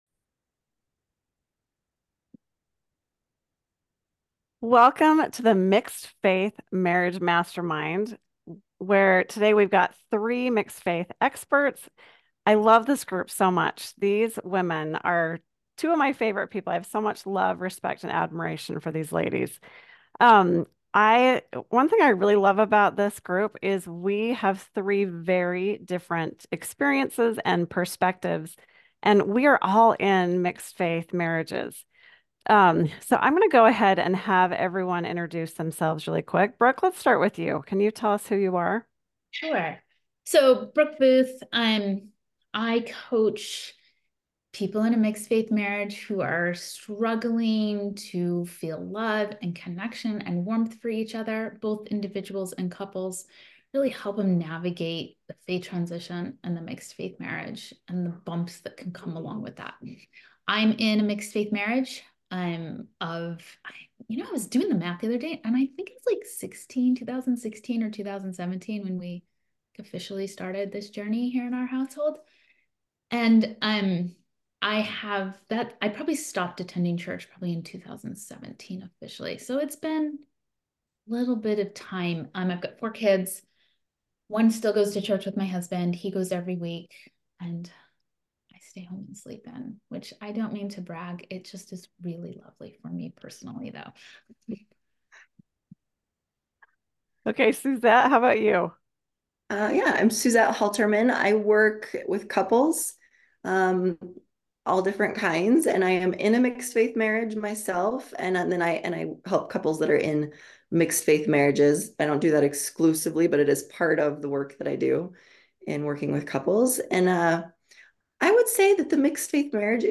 Mixed Faith Marriage Panel Discussion
This panel discussion is all about listening.